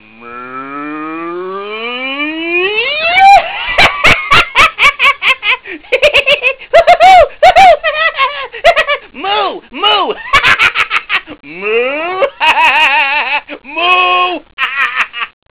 The sound of a stereotypical mad cow, mooing slightly madly